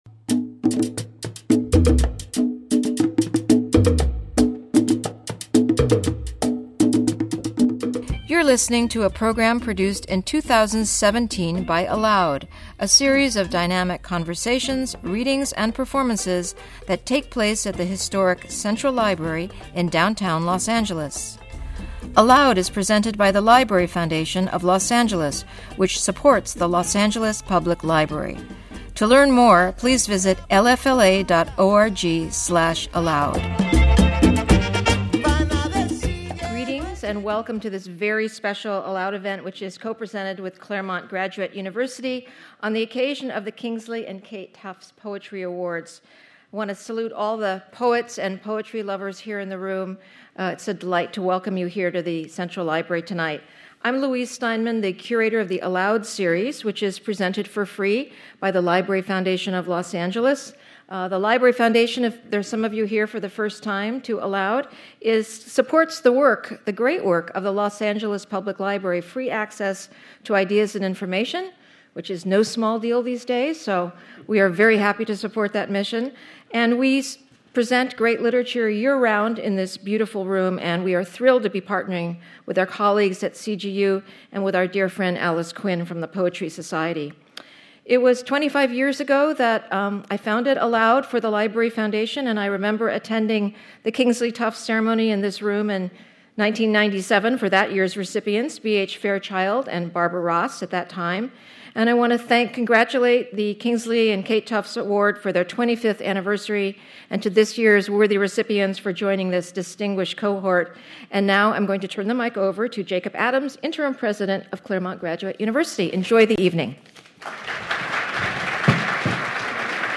25th Anniversary Celebration and Reading by 2017 Awardees